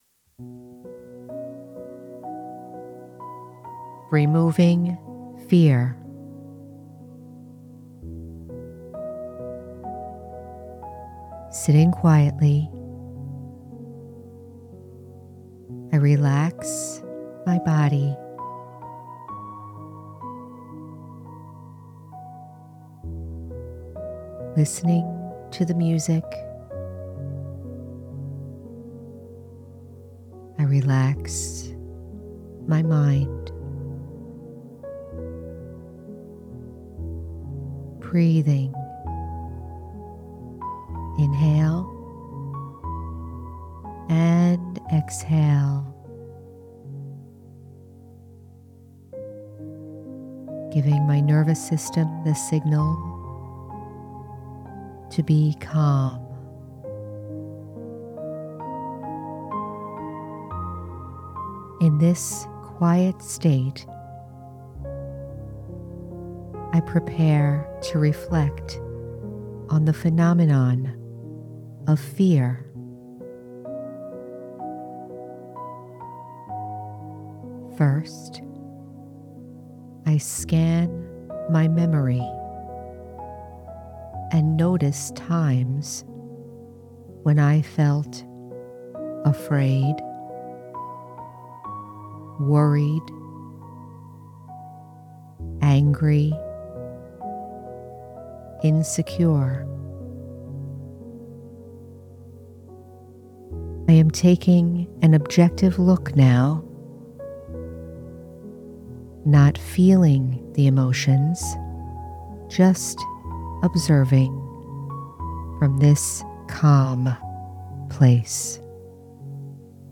Removing Fear- Guided Meditation- The Spiritual American- Episode 166